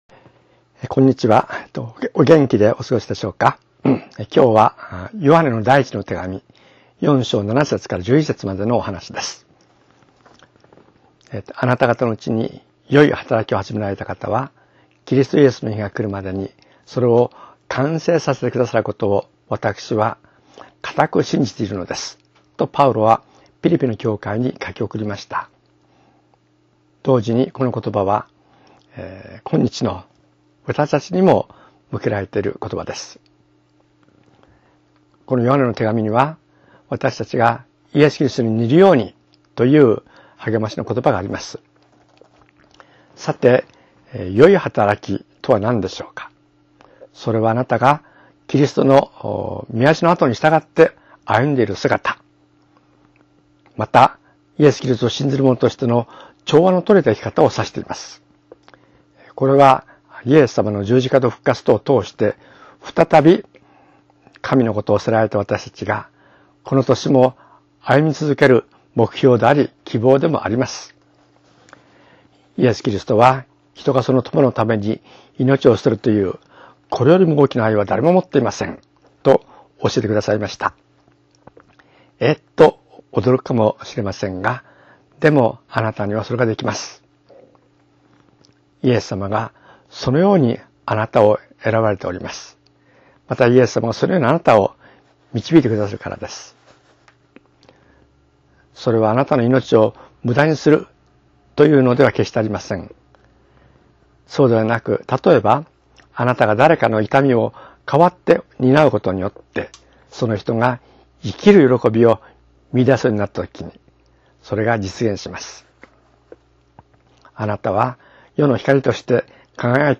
声のメッセージ